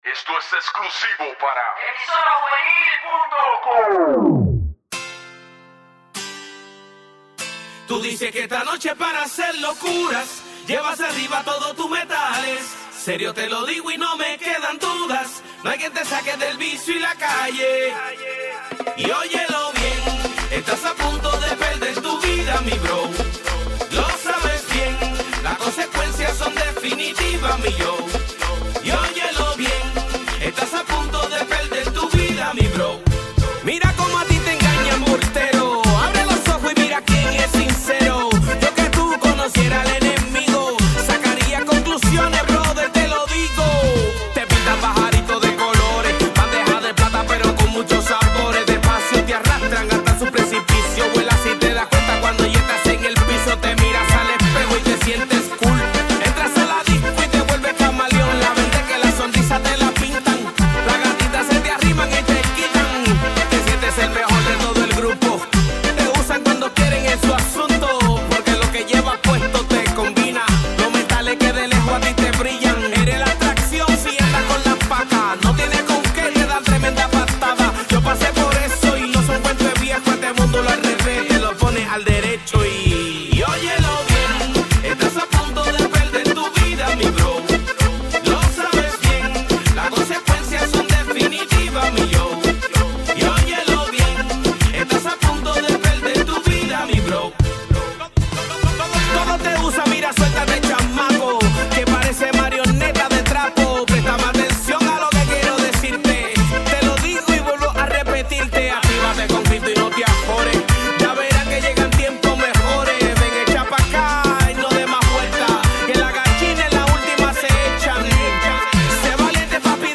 Música Cristiana